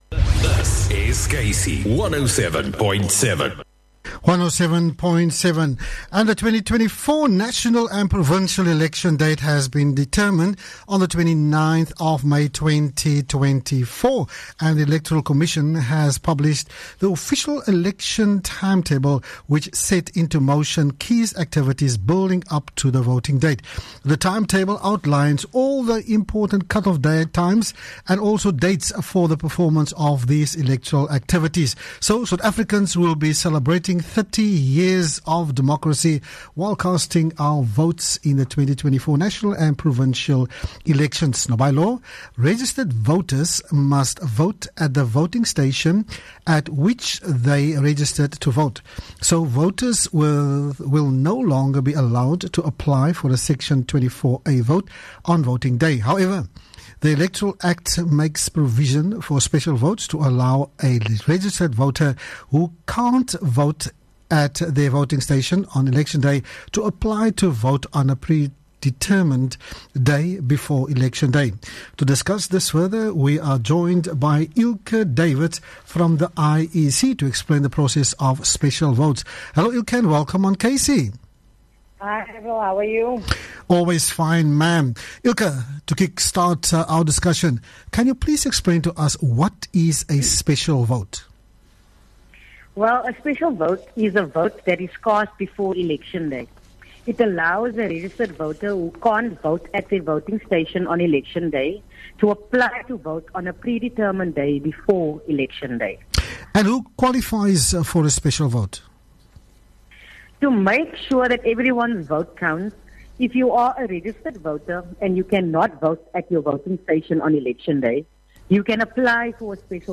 IEC interview